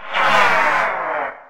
boogie_man_dies.ogg